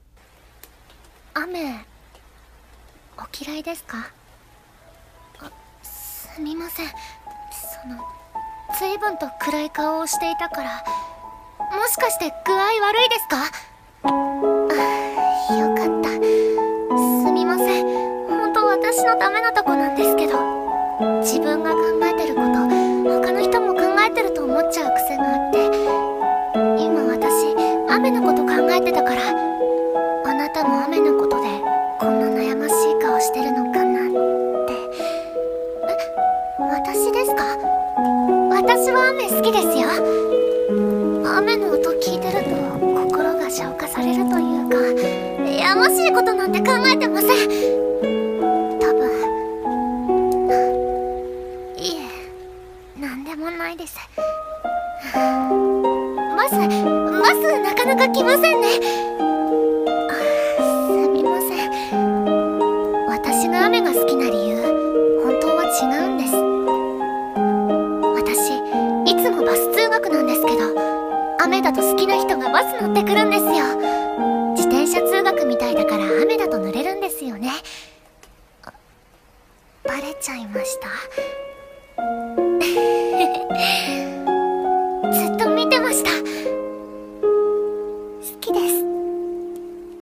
雨、バス待ち【一人声劇】